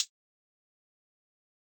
SouthsideHihat.wav